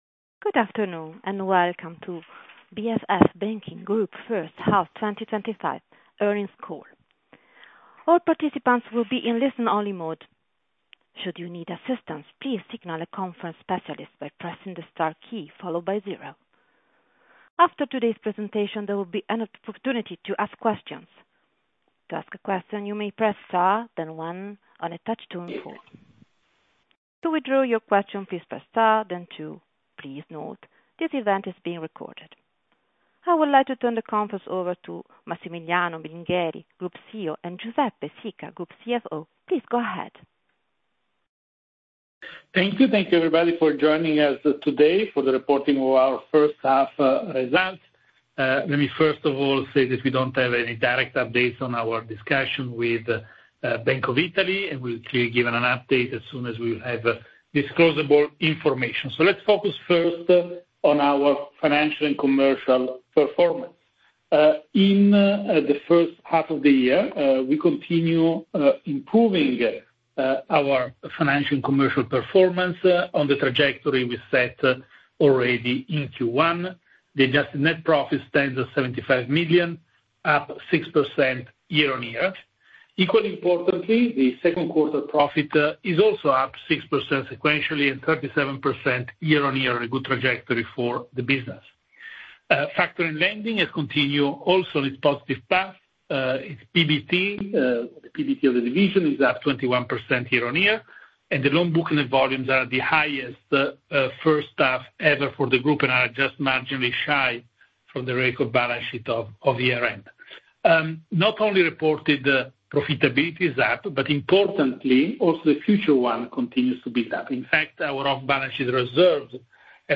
read more Earnings call recording